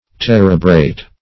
Search Result for " terebrate" : The Collaborative International Dictionary of English v.0.48: Terebrate \Ter"e*brate\, v. t. [L. terebratus, p. p. of terebrare, from terebra a borer, terere to rub.]